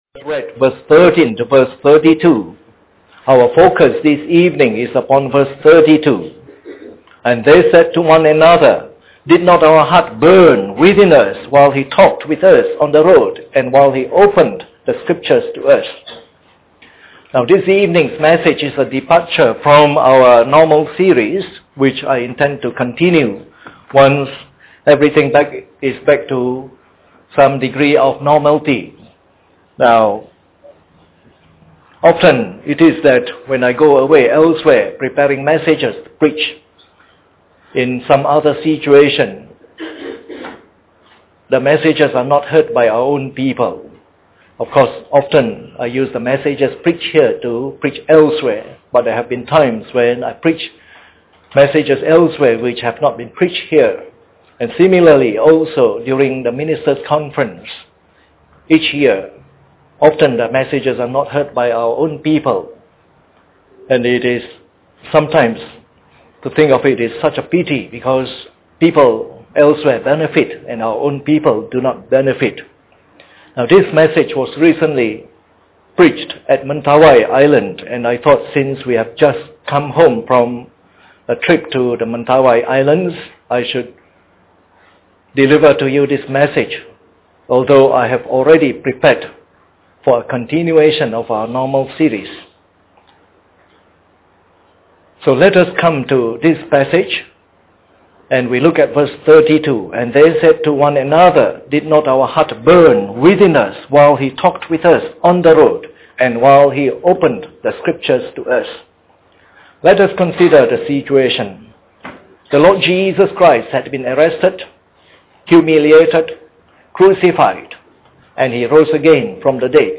Preached on the 11th of May 2008. This is a special message focusing on Luke 24:32.